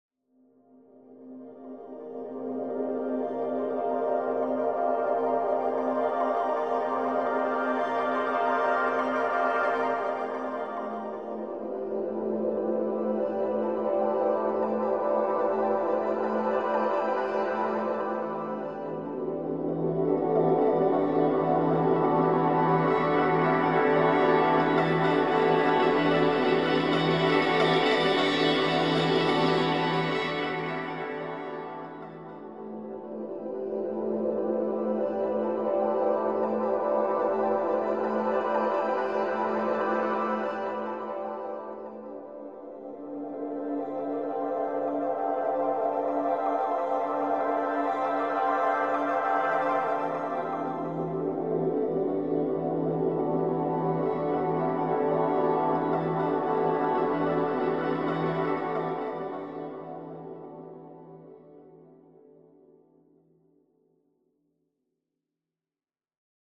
音声ファイル（効果音）